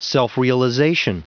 Prononciation du mot self-realization en anglais (fichier audio)
Prononciation du mot : self-realization